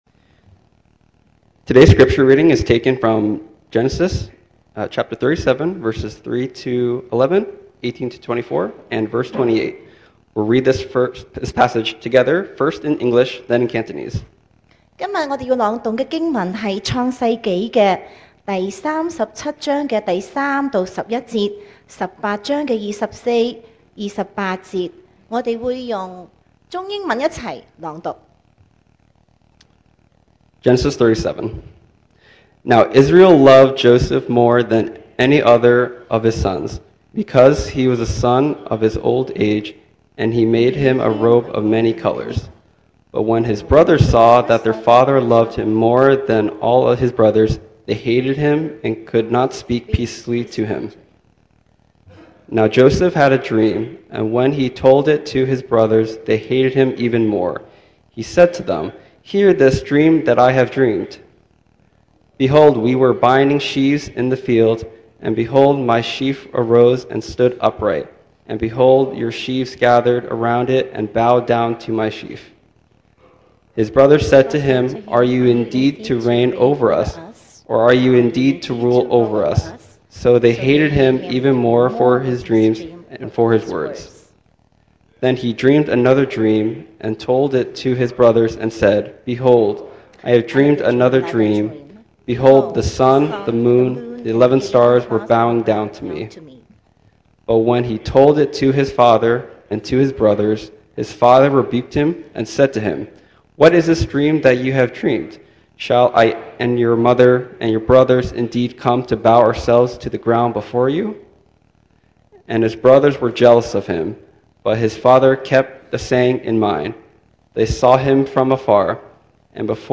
2025 sermon audios
Service Type: Sunday Morning